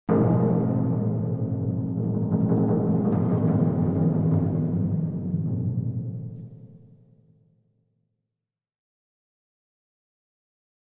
Timpani, (Mallets), Symphonic Crescendo, Type 3 - Strong First hit